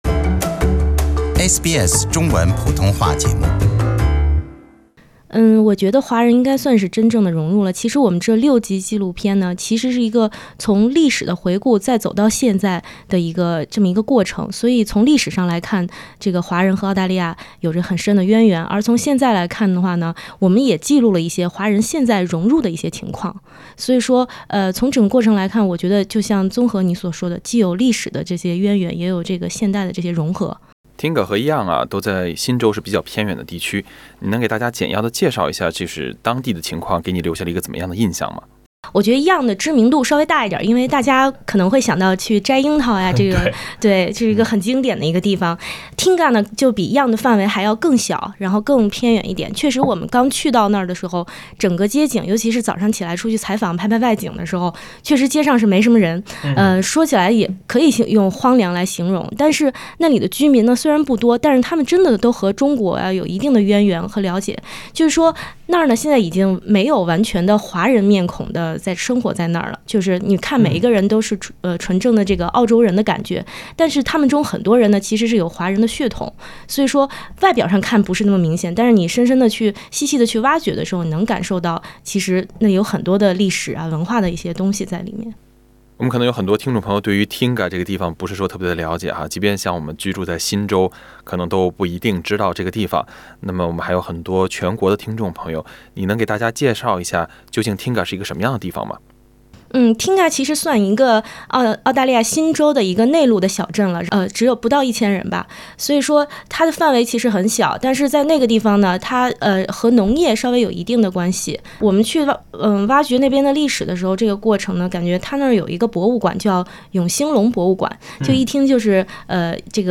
想了解更多华人来澳200周年的故事，请点击收听全部的采访内容。